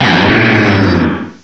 cry_not_haxorus.aif